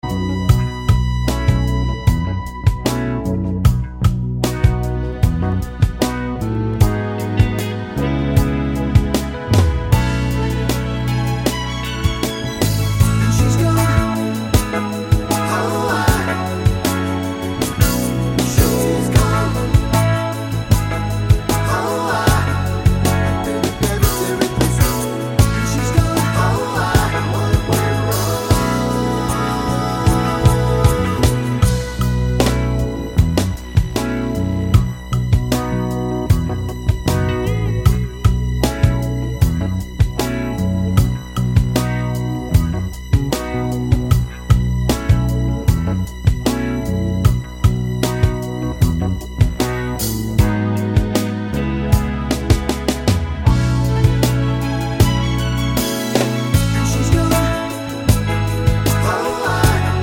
no sax solo Pop (1980s) 4:31 Buy £1.50